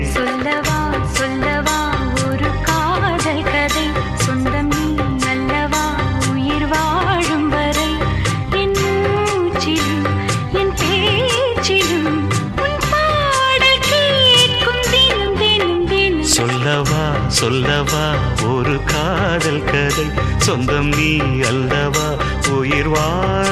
tamil ringtonelove ringtonemelody ringtoneromantic ringtone